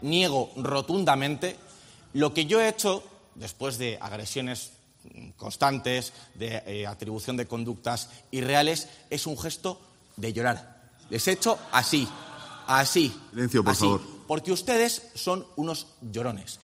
Juan García-Gallardo (Vox) niega haber dirigido un gesto de contenido sexual a la bancada del PSOE